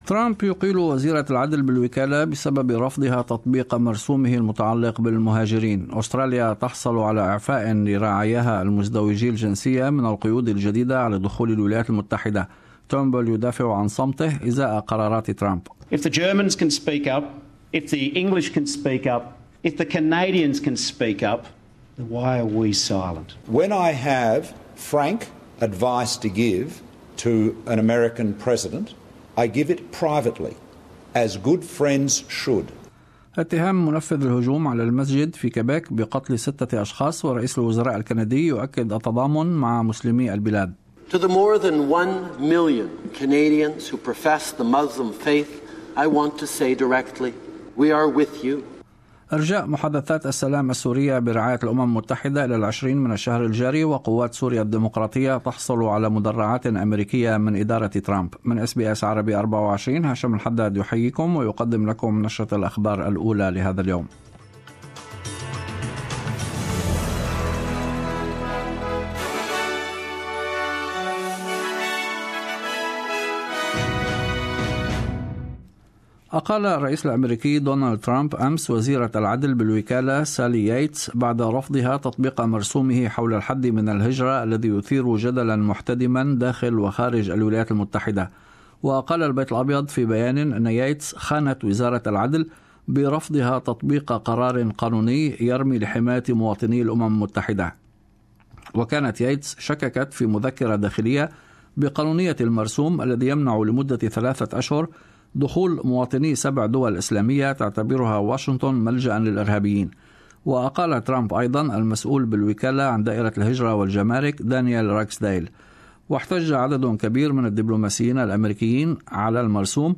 Morning News Bulliten